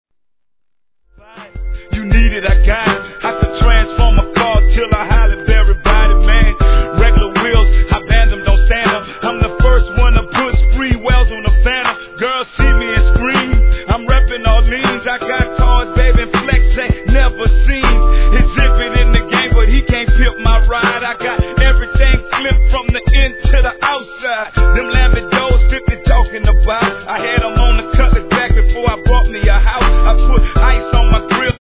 G-RAP/WEST COAST/SOUTH
心地よいバウンスが癖になりますね。